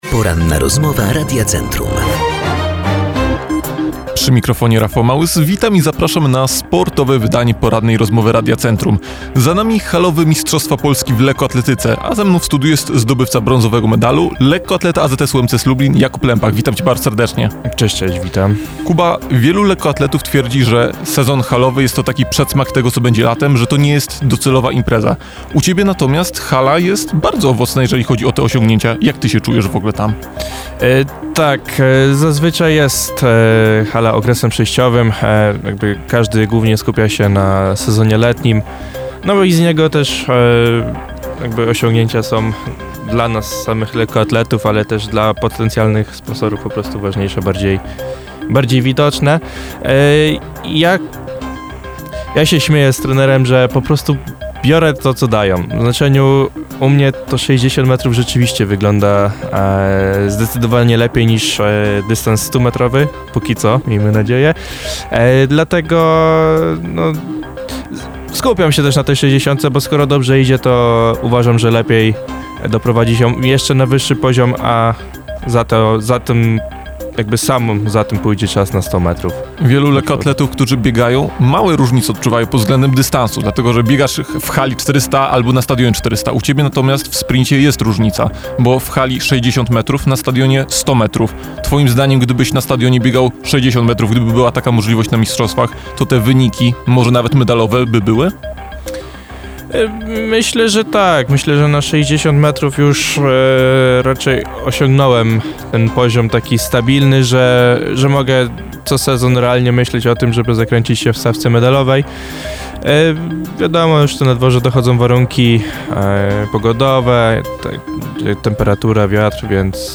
Cala-rozmowa-z-podkladem.mp3